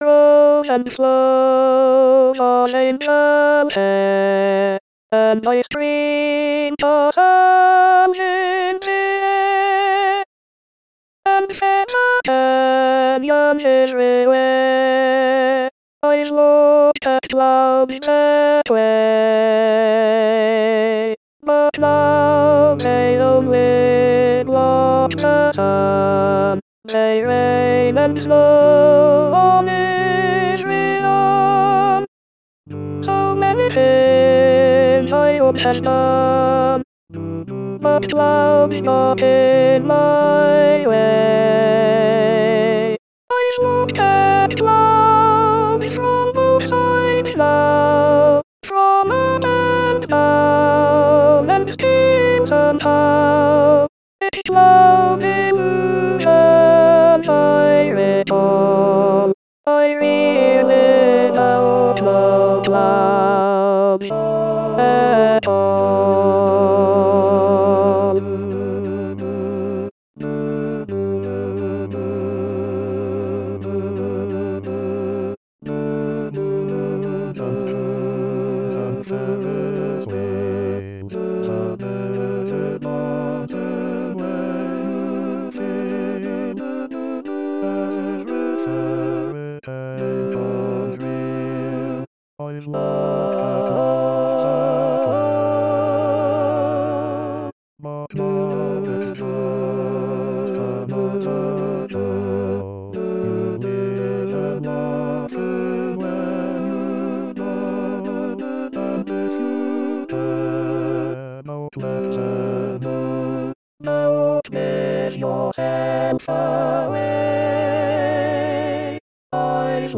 Alto Alto solo